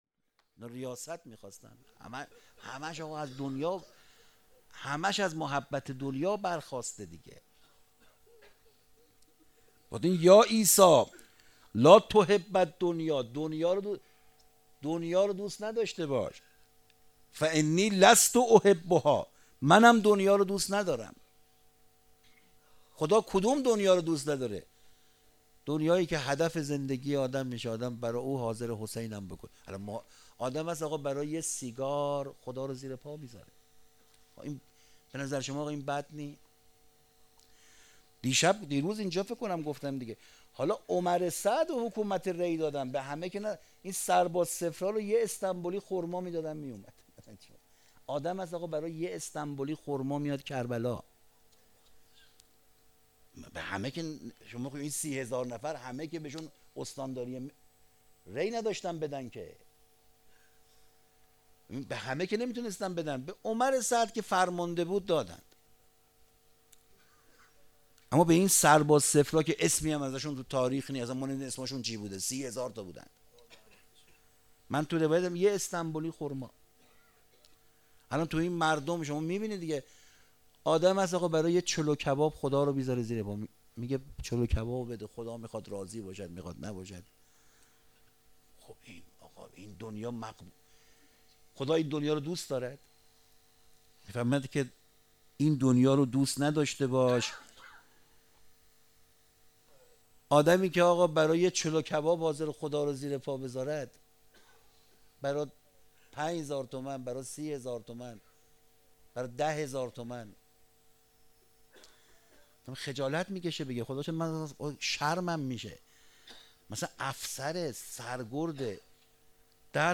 سخنرانی روز هشتم